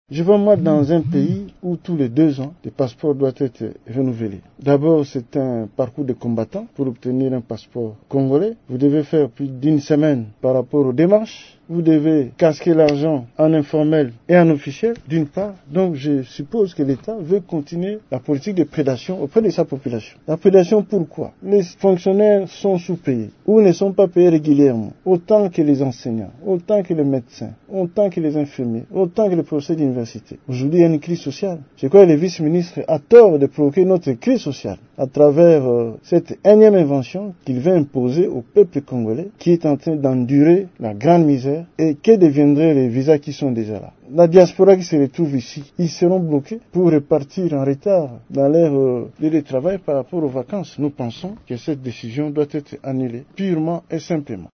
Dans un point de presse organisé dimanche 17 septembre à Kinshasa, le député membre du Rassemblement dénonce « une politique de prédation de la population », relevant que les démarches pour obtenir les passeports congolais nécessitent un budget exorbitant.